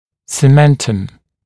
[sɪ’mentəm][си’мэнтэм]цемент (зуба), цементное вещество зубов, корешковая кора